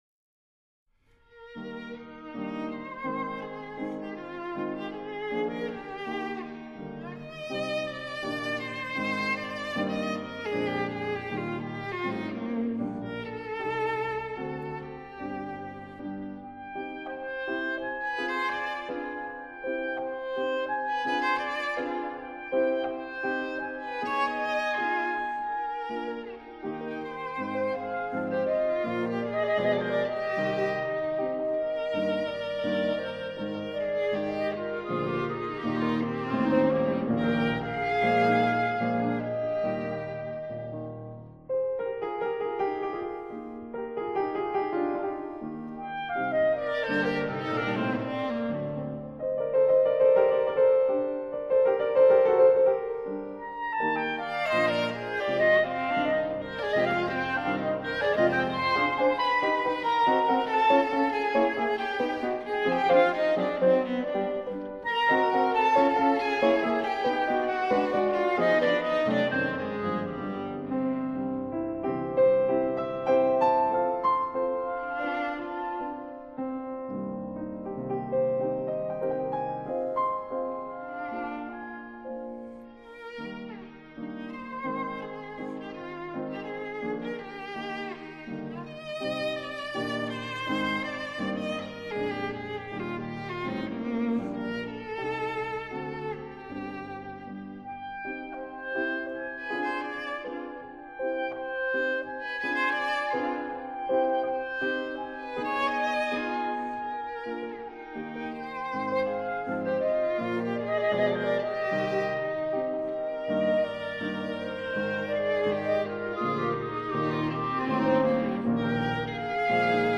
clarinet
viola
piano